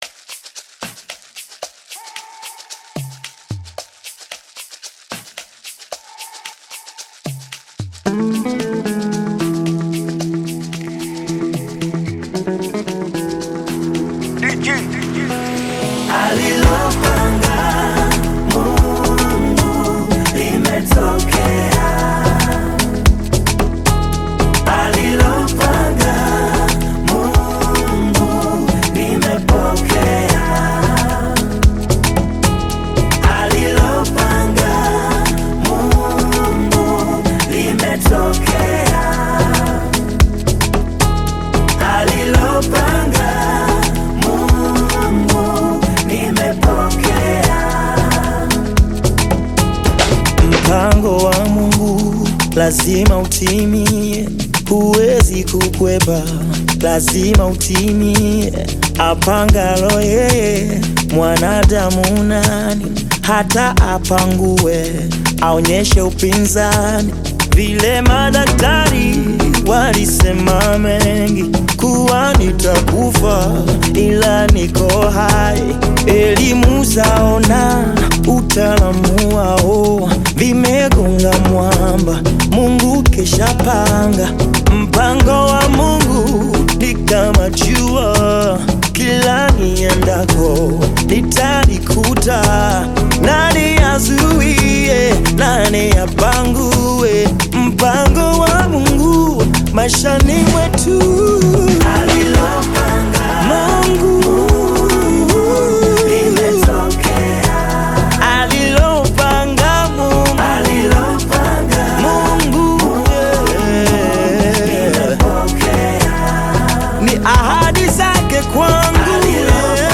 gospel song
African Music